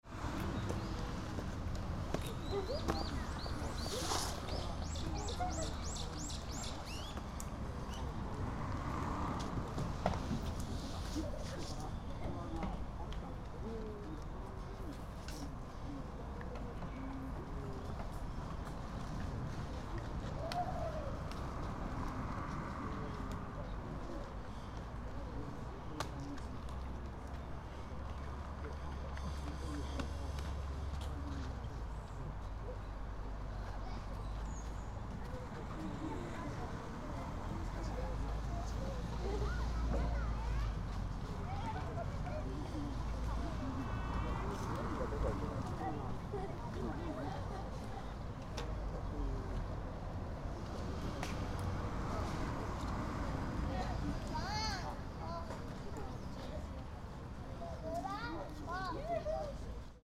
Mt. Shinobu Park
Children who attended the Eco Exploration Party (an environmental education program hosted by the Fukushima City Office) gathered at Mt. Shinobu Park one after the other.
Some younger children came to the park with their parents, who took the party members, and played with the playground equipment.